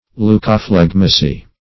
Search Result for " leucophlegmacy" : The Collaborative International Dictionary of English v.0.48: Leucophlegmacy \Leu`co*phleg"ma*cy\ (l[=u]`k[-o]*fl[e^]g"m[.a]*s[y^]), n. [Gr. leykoflegmati`a; leyko`s white + fle`gma phlegm: cf. F. leucophlegmasie.]
leucophlegmacy.mp3